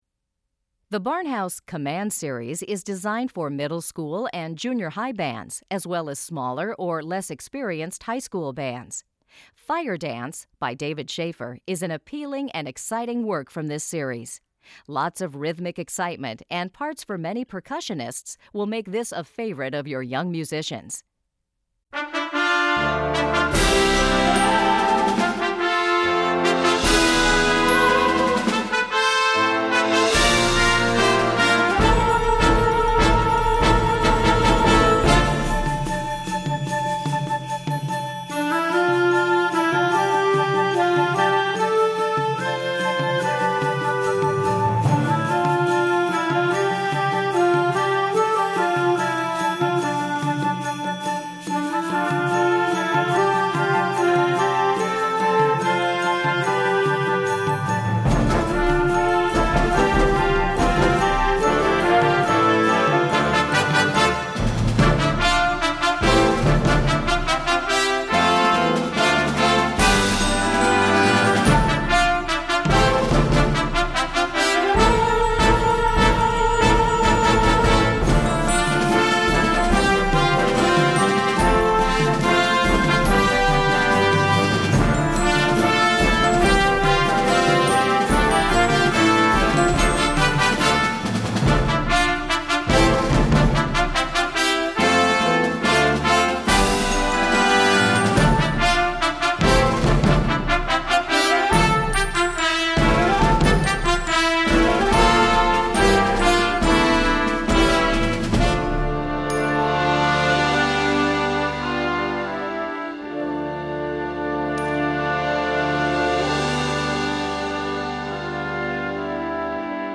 Gattung: Jugendwerk
Besetzung: Blasorchester
Big, bold and full of percussion